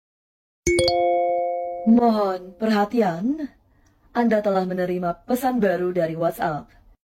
Notifikasi Suara Mba mba bandara📳📞🤭🫨🫡🫣 sound effects free download